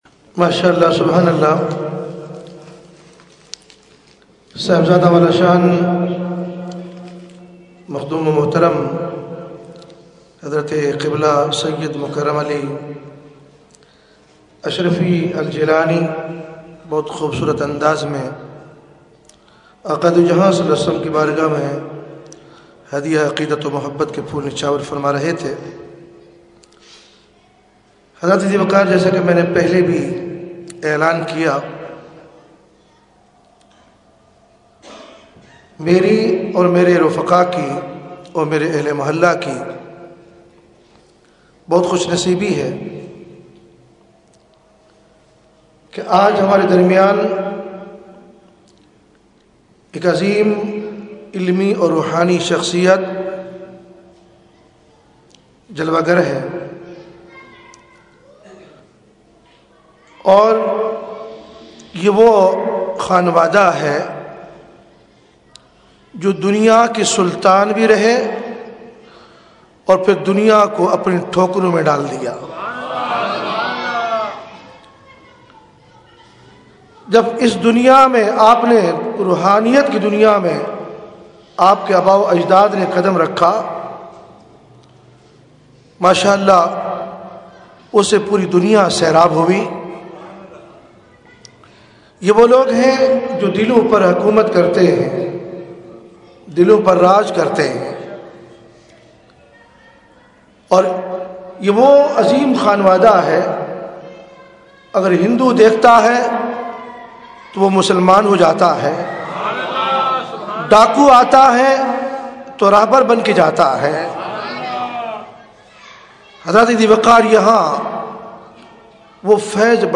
Category : Announcement | Language : UrduEvent : Eid Milad Usmani Masjid 22 January 2013